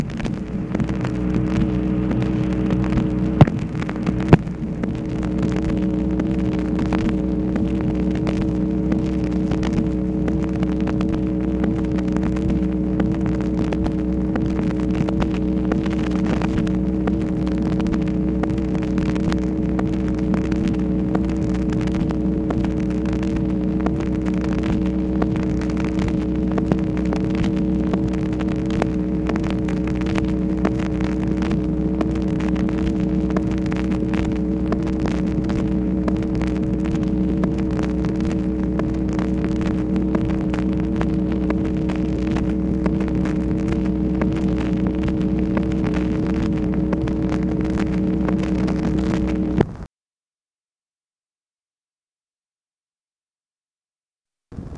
MACHINE NOISE, November 3, 1964
Secret White House Tapes | Lyndon B. Johnson Presidency